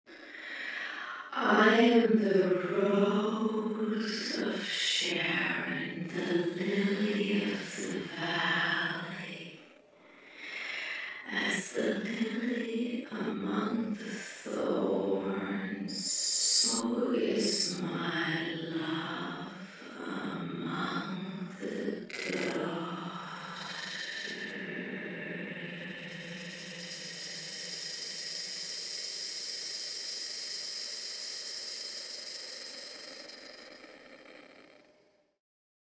In the bar above the sample window, once we start the playback, we will hear the stretching occur as soon as we move the cursor away from 100%, small stretches at first, then to the maximum stretch of 1%, or “frozen” at 0%, or with a backwards movement through the sample at negative values. The manual control is very responsive to your mouse movement and it is relatively easy to impose longer durations on significant words, such as “rose, lily, valley” in this example, then end with a very long stretch on the final sibilant.